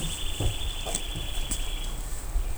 Vogelstimmen: Zaunkönig,
kleiber.wav